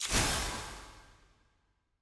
sfx-eog-ui-platinum-burst.ogg